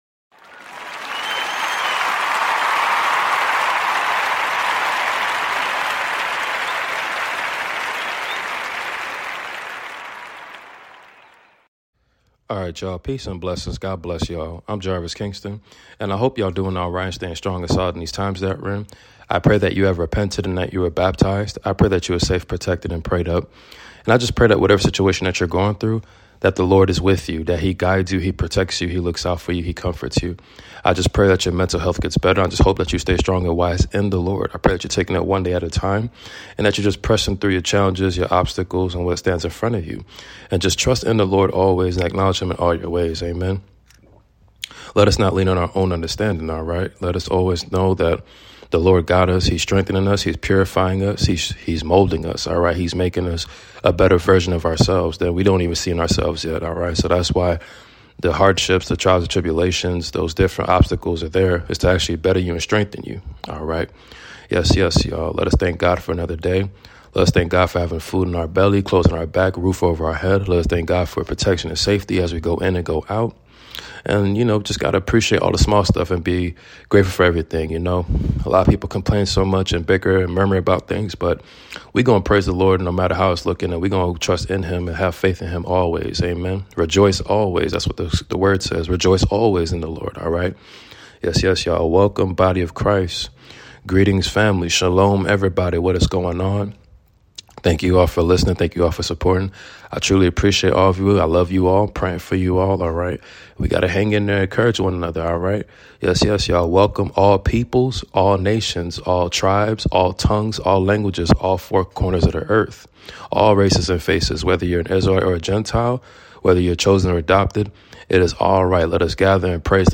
Book of Isaiah 44-66 reading ! Rejoice and have joy!